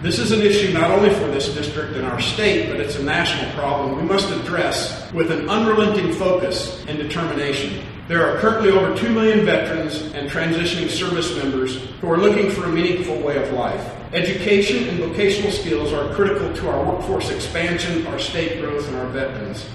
A crowd of about 20 people gathered at the Union Pacific Depot in downtown Manhattan Tuesday